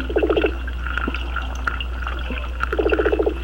I recorded some weakfish,
Here is a clip of a train of thumps made by a weakfish as it attacked a school of silversides, Menidia menidia, aggregated under the dock lights. If you listen carefully you can here me say "Whoa!" when I heard the sound and saw the fish flash.
weakfish1.wav